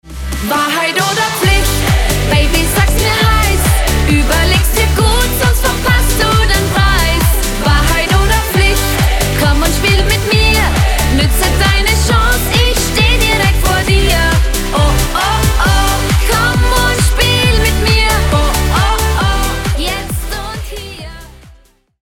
sorgt für ordentlich Bewegung auf der Tanzfläche